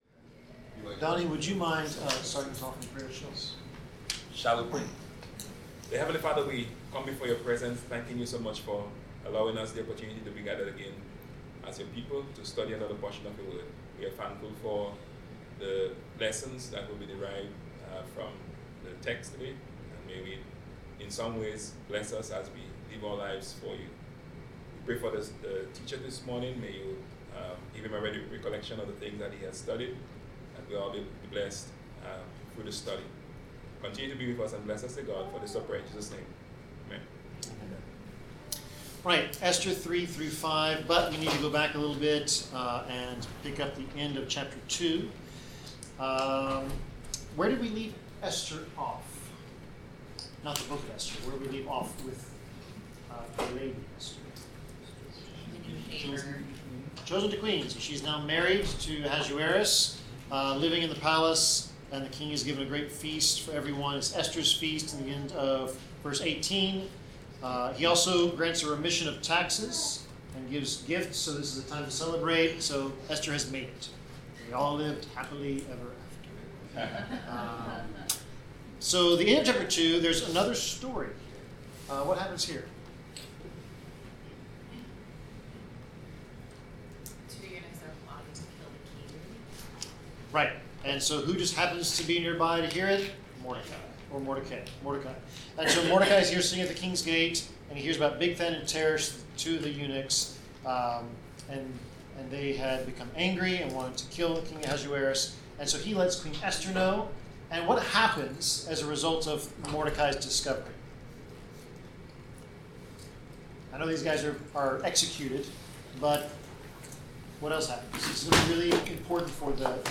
Bible class: Esther 3-5
Service Type: Bible Class Topics: Arrogance , Diplomacy , Honor , Idolatry , Jesus , Leadership , Pride , Respect , Service , Worship « The Book of Signs